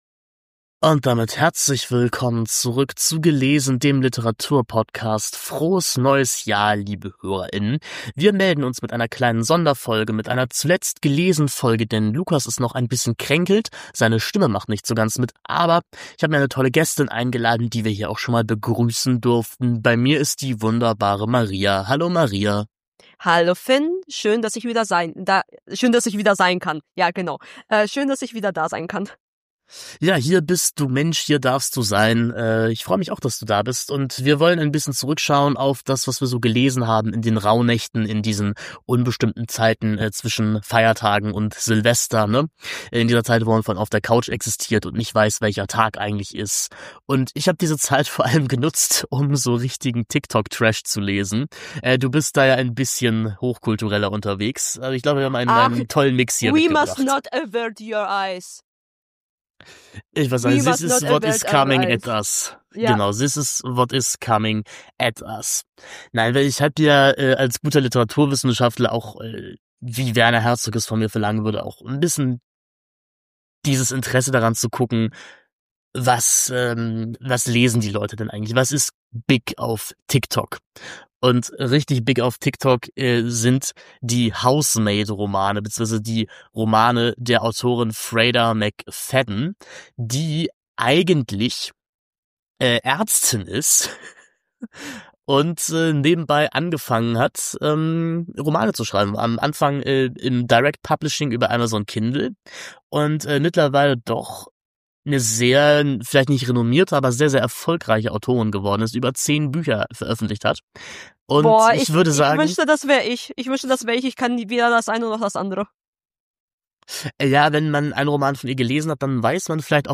schauen in dieser heiter beschwingten Folge gut gelaunt und frisch frisiert auf ihre Lektüreausbeute der letzten Wochen zurück. Es gibt TikTok-Trash, Soziologie, Vampire, Spice (nicht was ihr denkt) und junge deutsche Literatur.